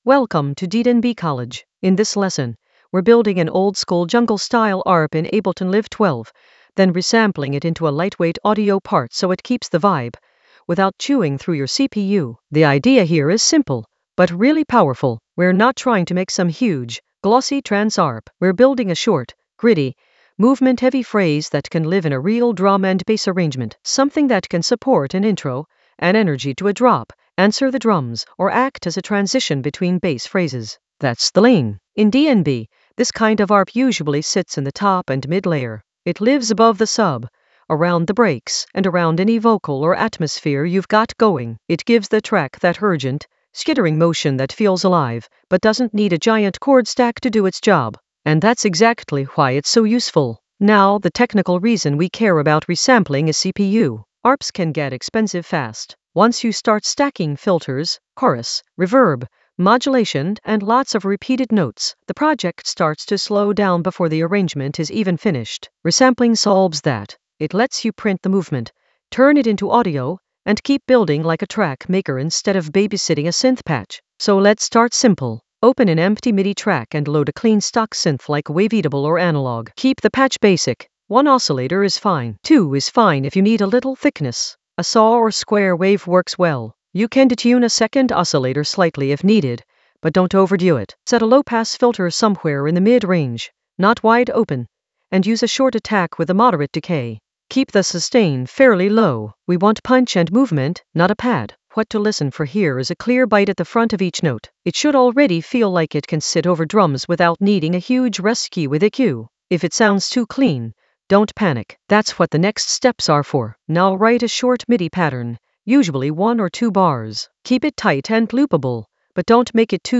An AI-generated beginner Ableton lesson focused on Arrange oldskool DnB jungle arp with minimal CPU load in Ableton Live 12 in the Resampling area of drum and bass production.
Narrated lesson audio
The voice track includes the tutorial plus extra teacher commentary.